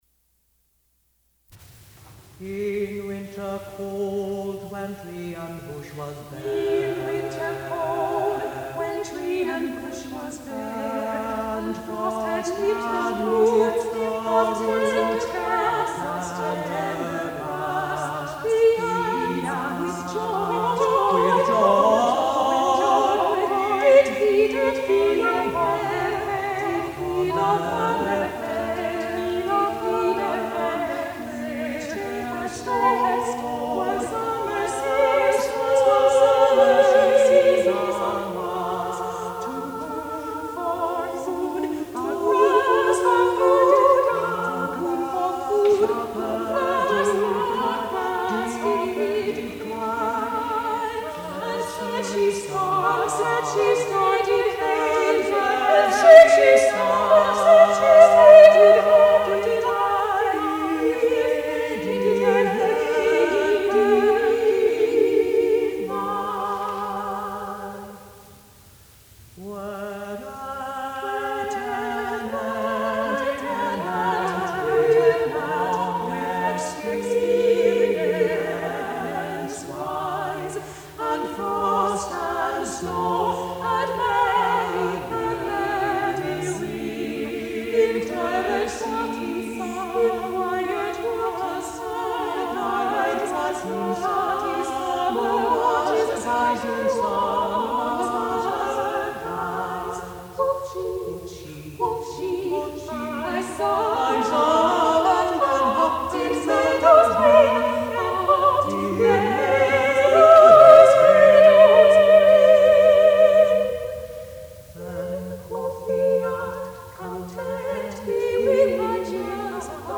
soprano
tenor